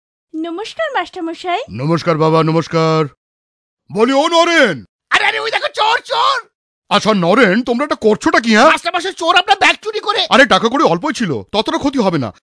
Radio PSA